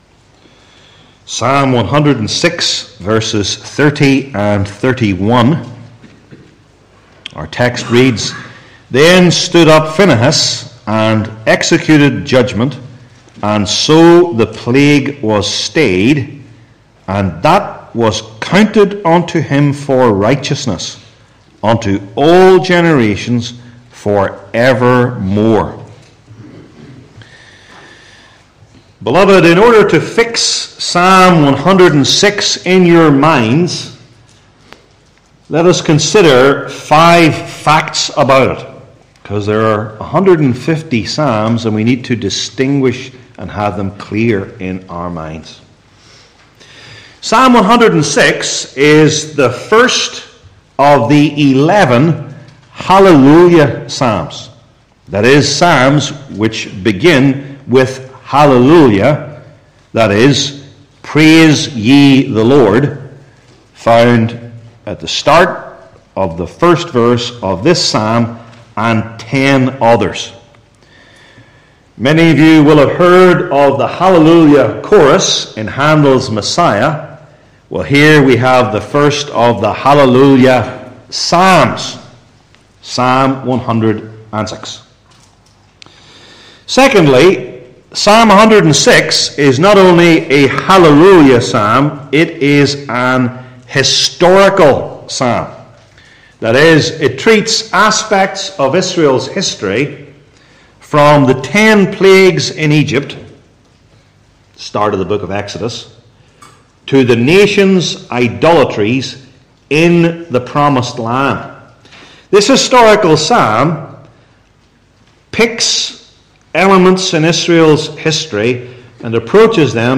Old Testament Sermon Series I. Justification?